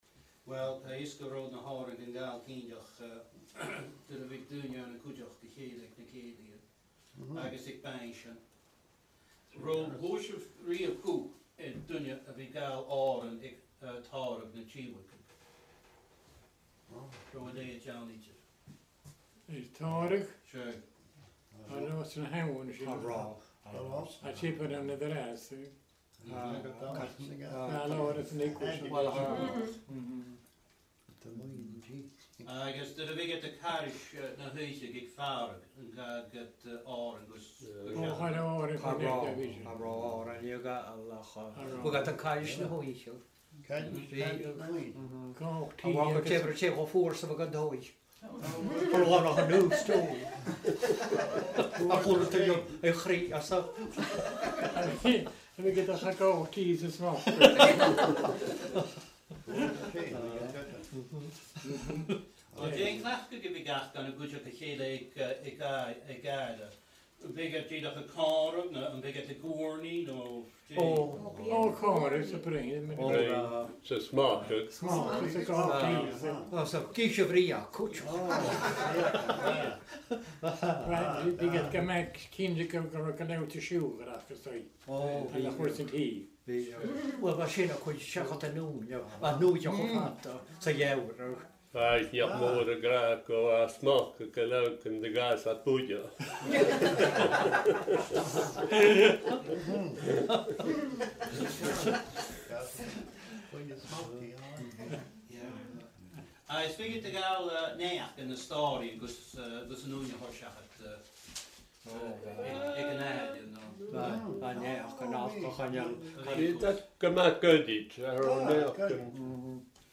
An Clachan Gàidhealach, Sanndraigh
Agallamh